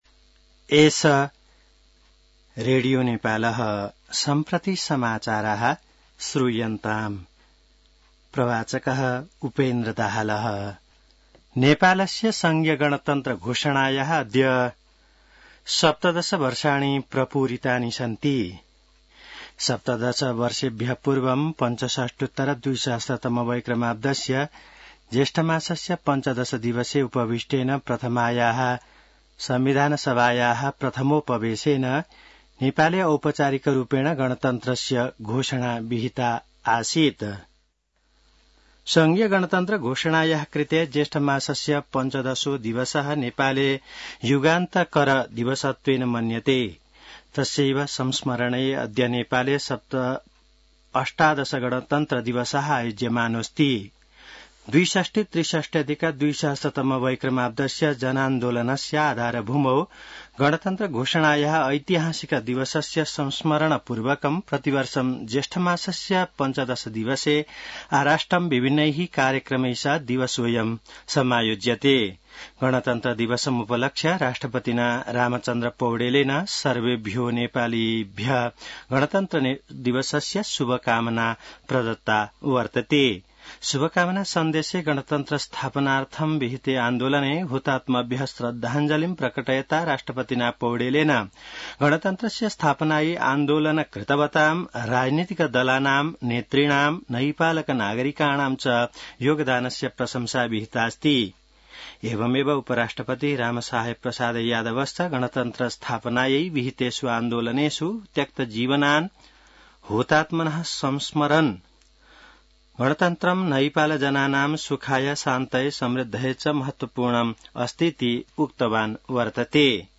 संस्कृत समाचार : १५ जेठ , २०८२